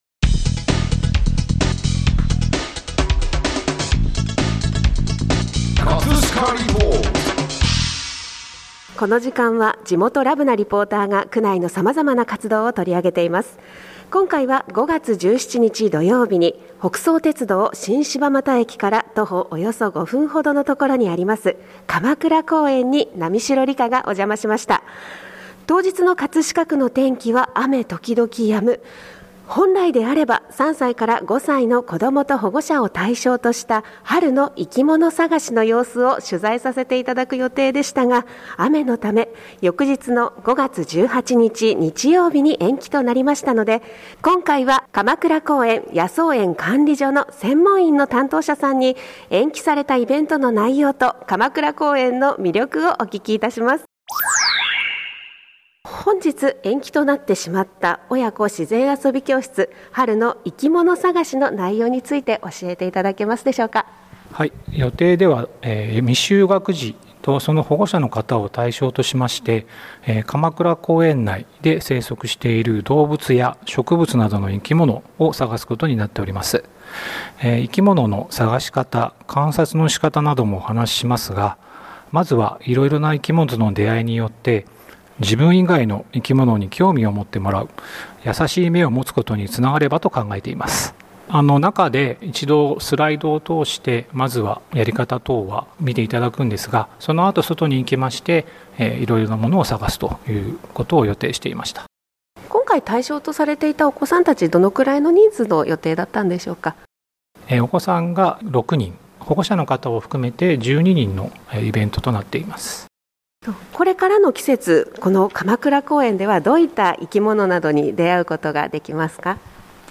▼リポート音声